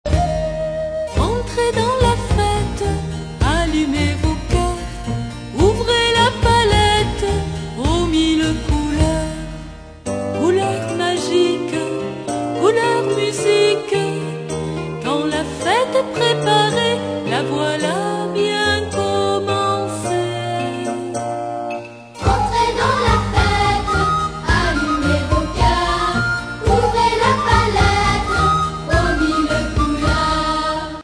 13 chants pour l'éveil à la Foi des plus jeunes.